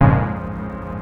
HOUSE 7-R.wav